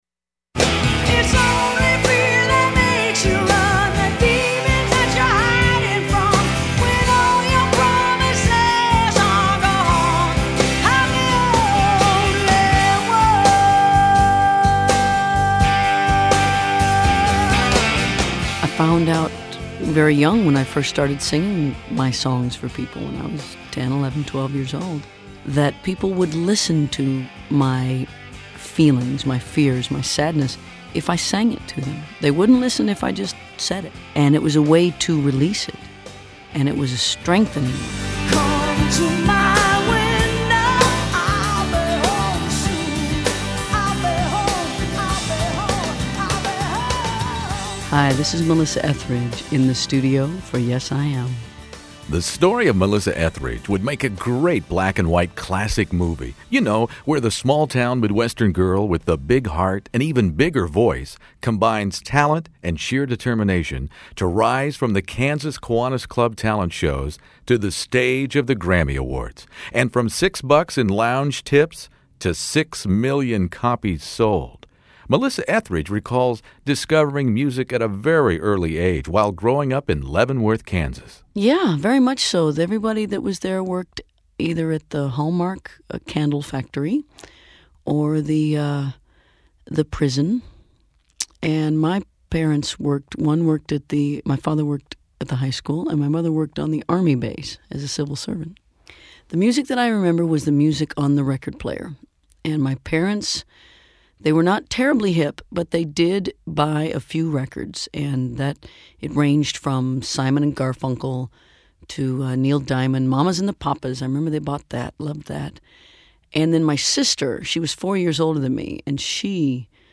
Melissa Etheridge "Yes I Am" interview In the Studio